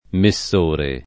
vai all'elenco alfabetico delle voci ingrandisci il carattere 100% rimpicciolisci il carattere stampa invia tramite posta elettronica codividi su Facebook messore [ me SS1 re ] s. m. — latinismo poet. per «mietitore»